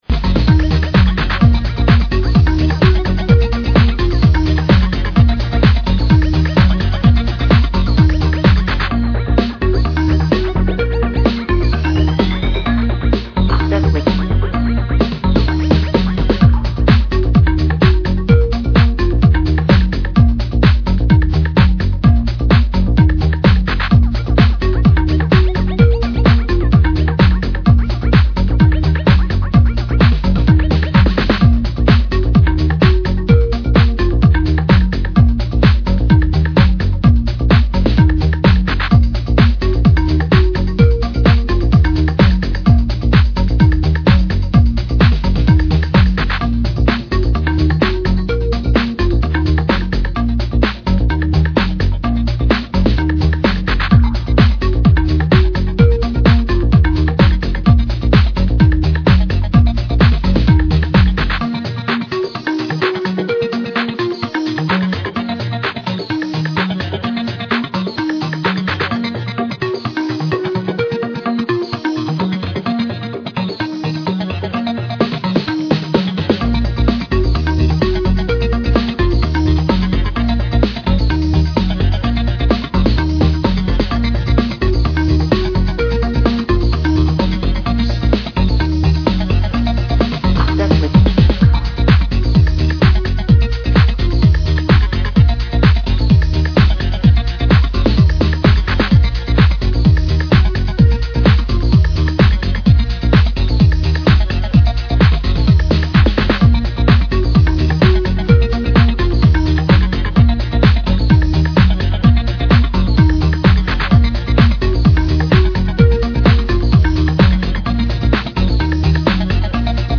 Spacious and airy